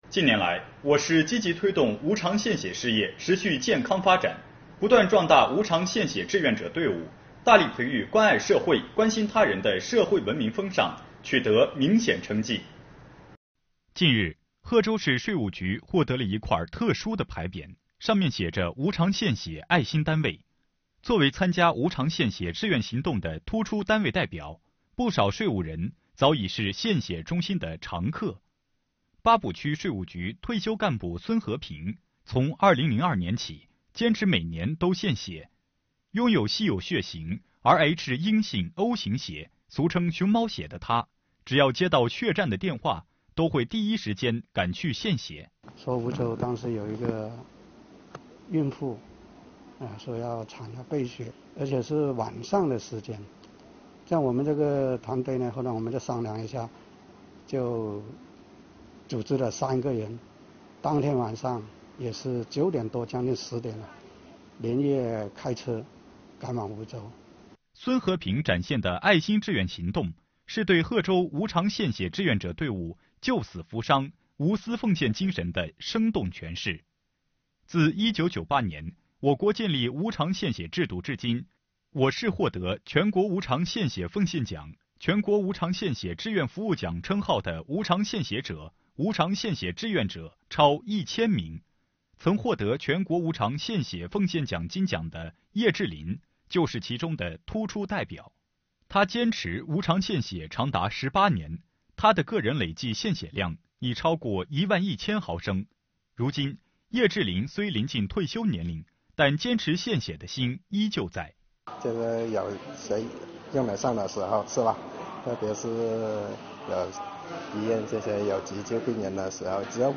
近日，贺州电视台发表了我市无偿献血事业持续健康发展的有关报道，其中提到，贺州市税务干部积极参与无偿献血，为社会公益作出贡献。